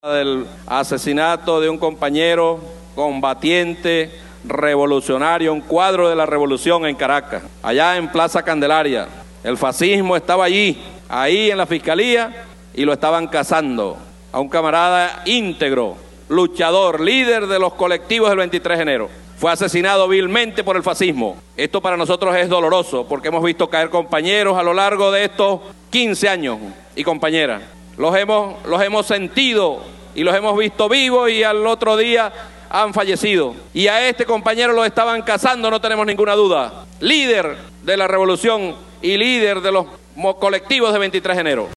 Declaraciones del Presidente de la Asamblea Nacional de Venezuela, Diosdado Cabello